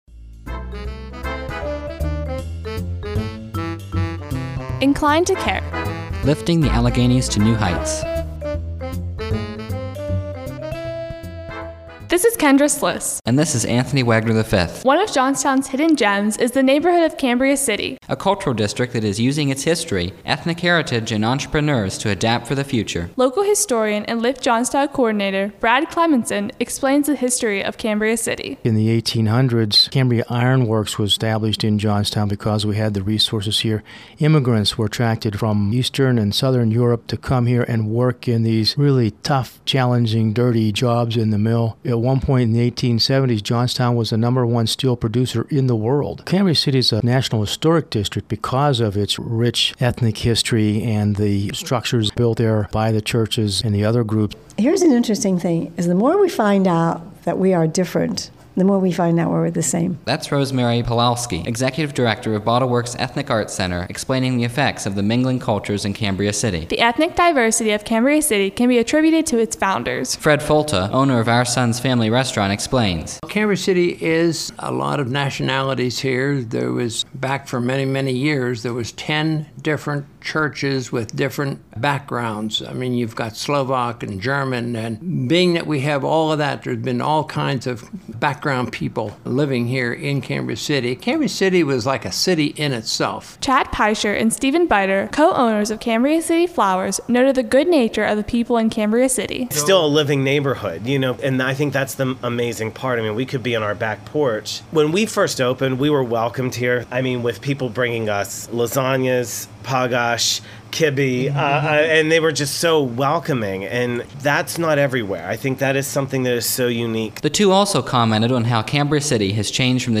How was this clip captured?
After initial training at SLB Radio Productions in Pittsburgh, students were provided with microphones, digital recorders, editing software and ongoing coaching to create these succinct broadcast features.